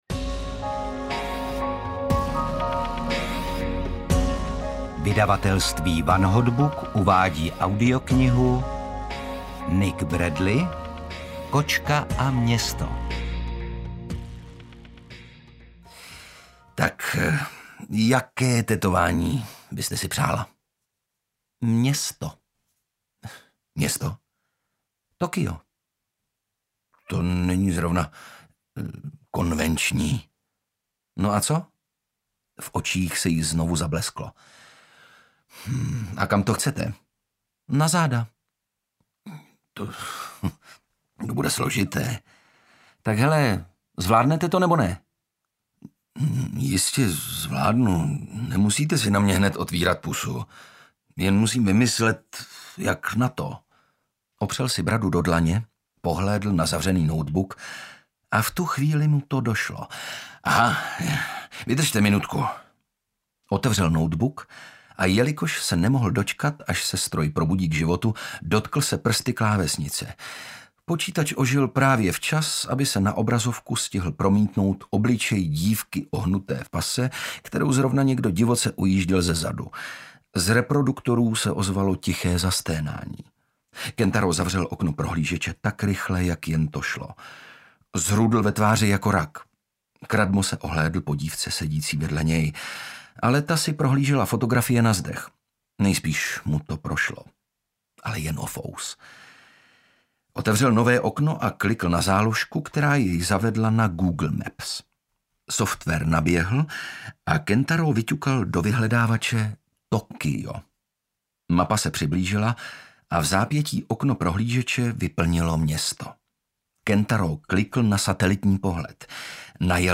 Kočka a město audiokniha
Ukázka z knihy